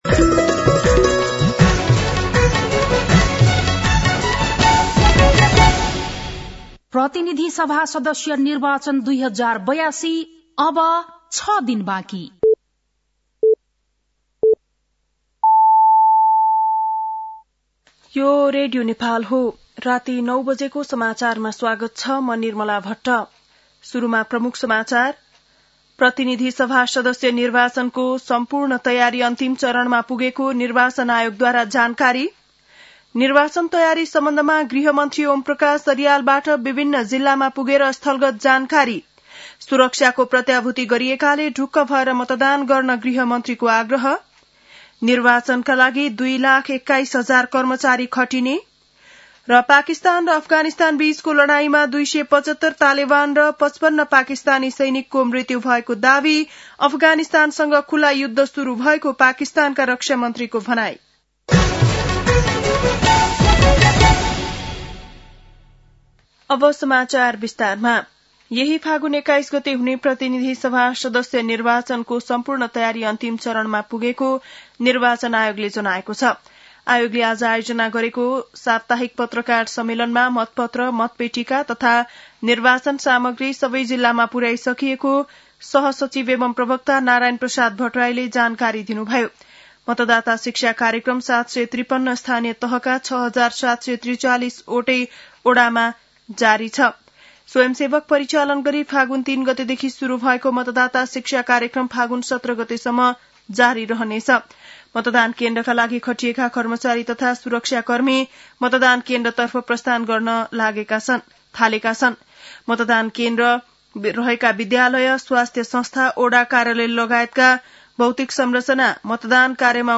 बेलुकी ९ बजेको नेपाली समाचार : १५ फागुन , २०८२